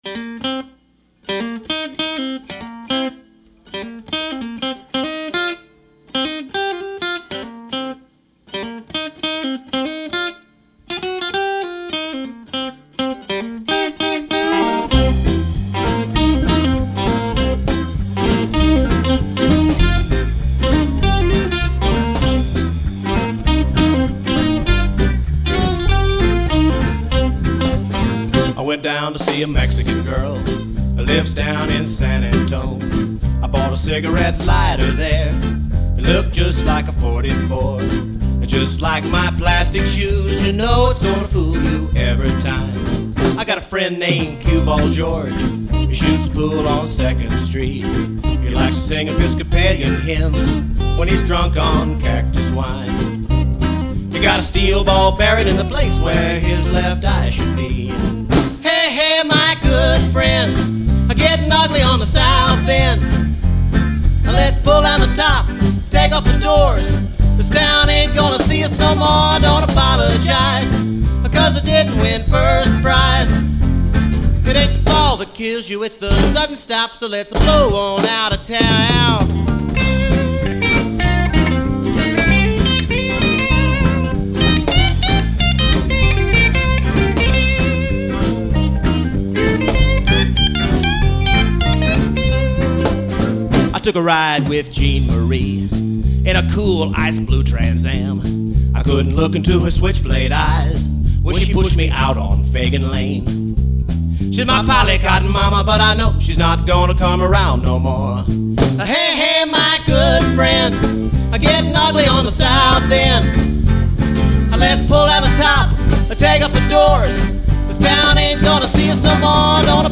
This is the only time I ever doubled vocals.